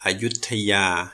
อยุธยา [a\ yut/ tha/ ya:--] - also starting with อย - does not belong in this group.